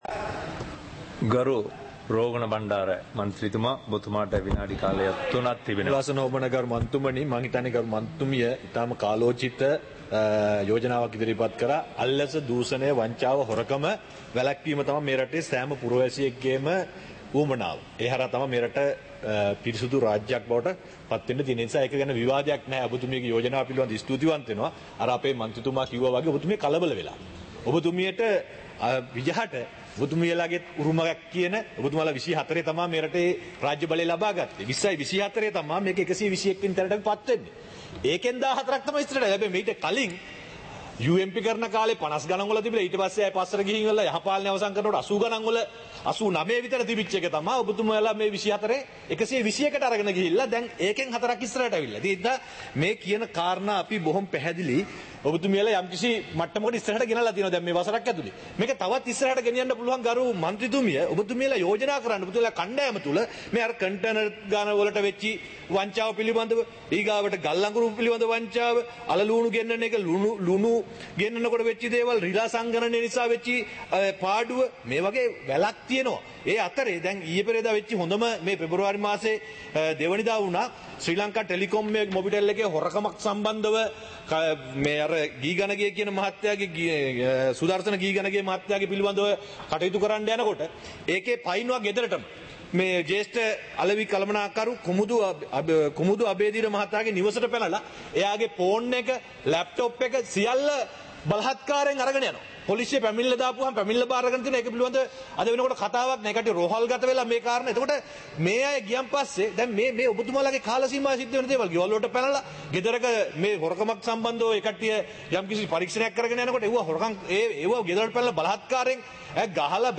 இலங்கை பாராளுமன்றம் - சபை நடவடிக்கைமுறை (2026-02-18)